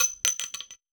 weapon_ammo_drop_23.wav